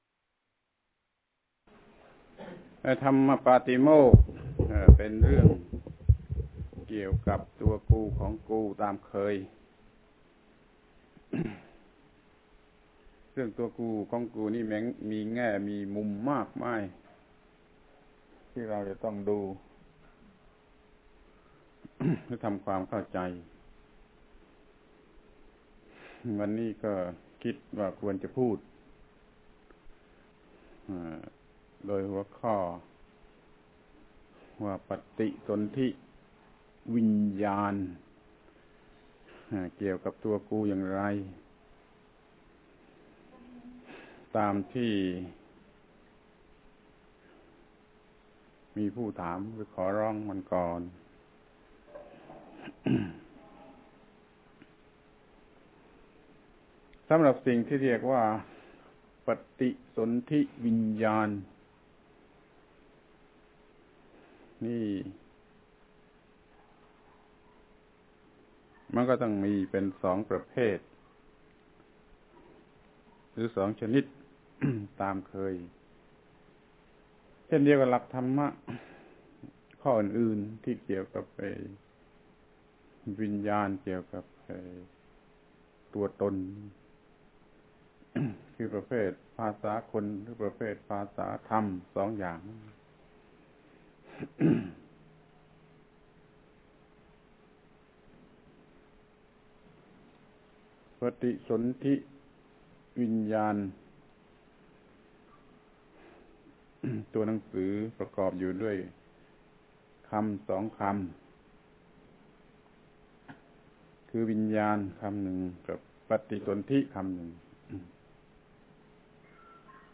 ฟังธรรมะ Podcasts กับ พระธรรมโกศาจารย์ (พุทธทาสภิกขุ)